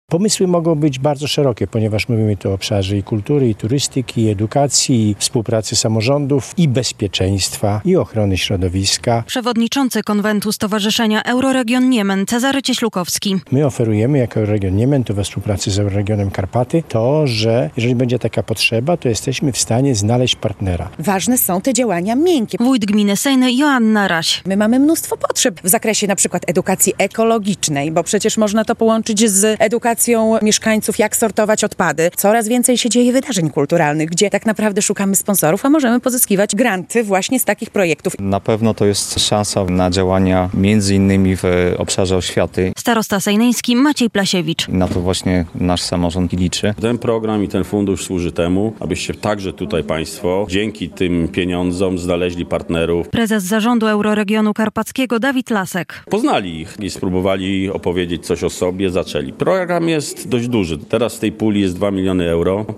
Ruszył nabór do Funduszu Małych Projektów - relacja